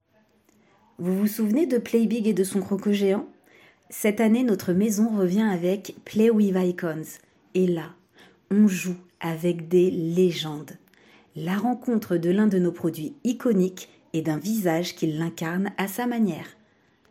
Essai voix off tension